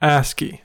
Ääntäminen
IPA : [ˈæski]